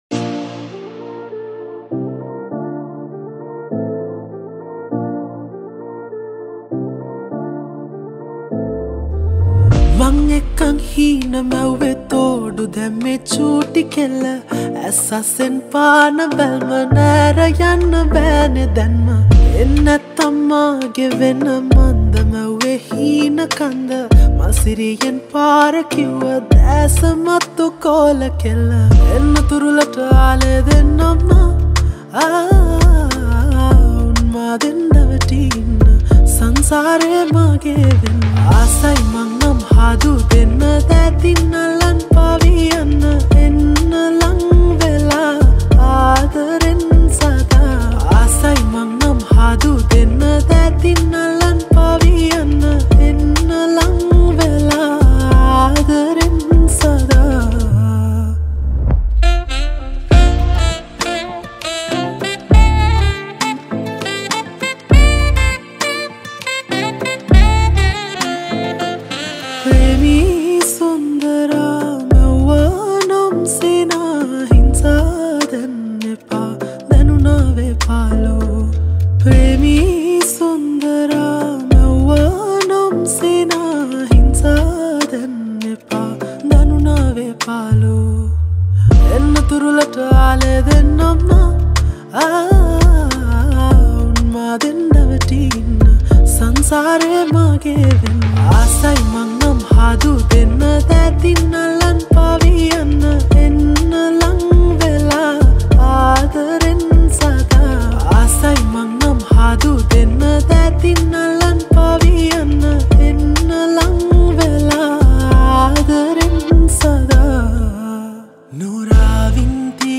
Releted Files Of Sinhala Mashup Songs